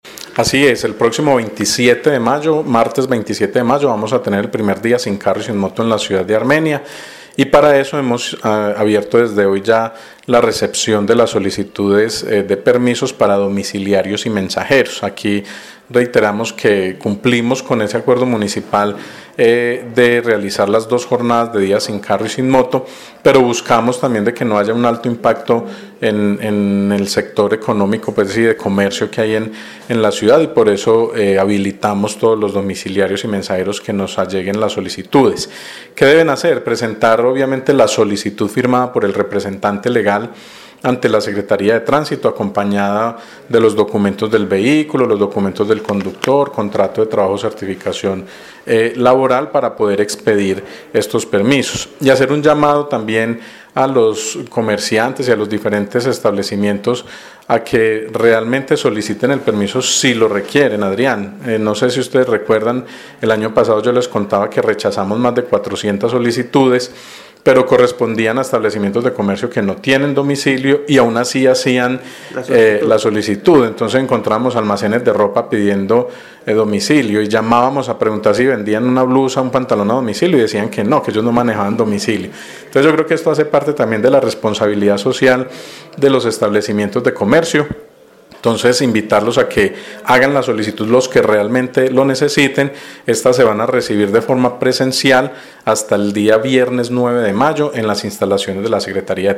En Caracol Radio Armenia hablamos con el secretario de tránsito y transporte, Setta, Daniel Jaime Castaño sobre este tema expresó “recordar que el martes 27 de mayo, vamos a tener el primer día sin carro y sin moto en la ciudad de Armenia y para eso hemos abierto desde hoy ya la recepción de las solicitudes de permisos para domiciliarios y mensajeros”